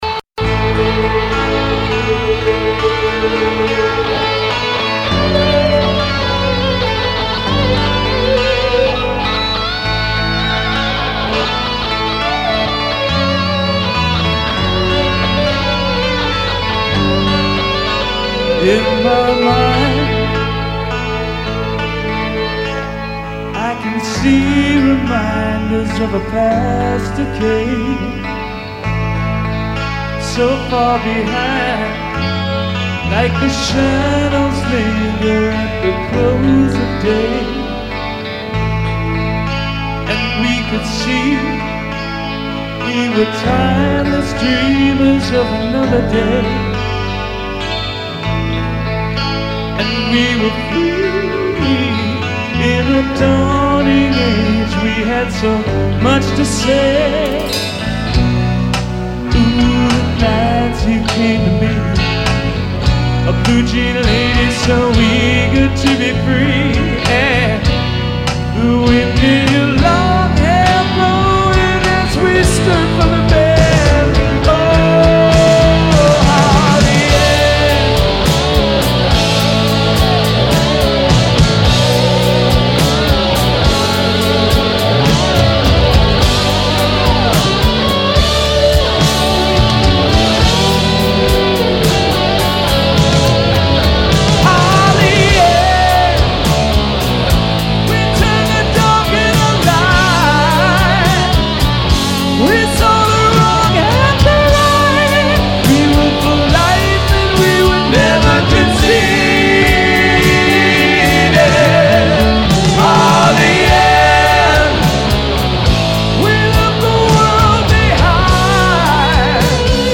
Hammond B3 organ